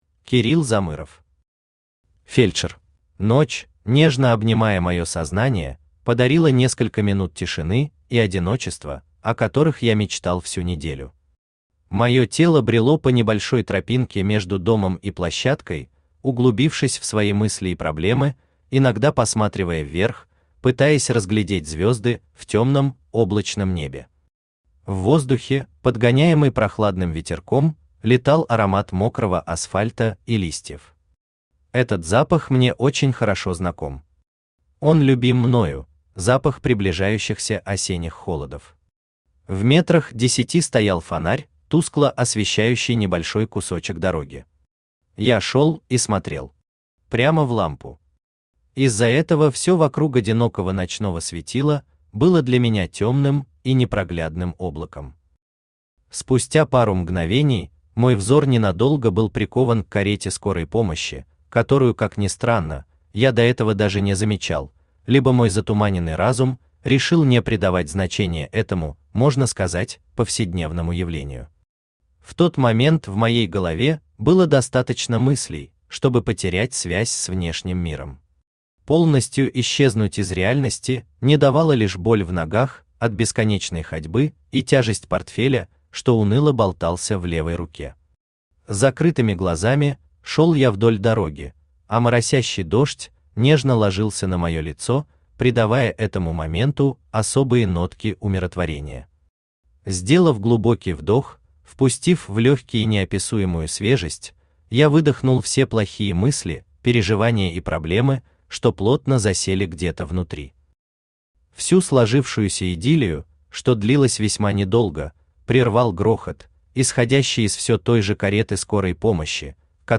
Аудиокнига Фельдшер | Библиотека аудиокниг
Aудиокнига Фельдшер Автор Кирилл Олегович Замыров Читает аудиокнигу Авточтец ЛитРес.